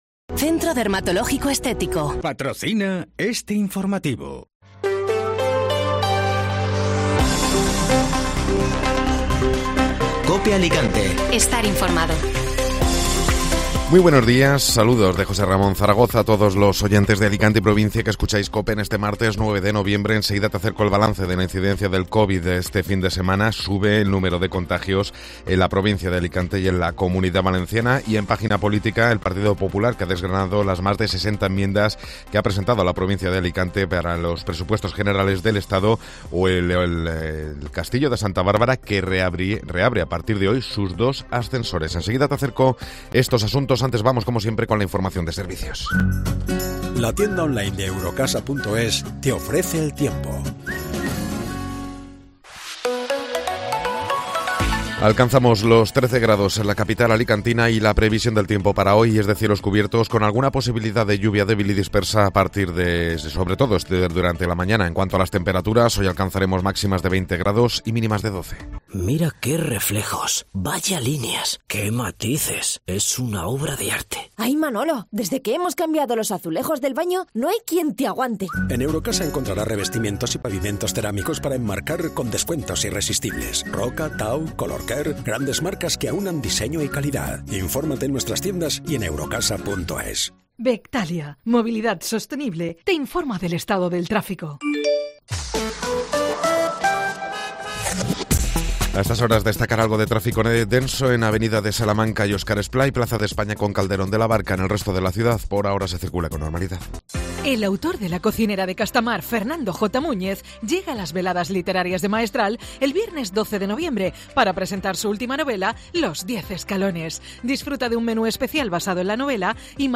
Informativo Matinal (Martes 9 de Noviembre)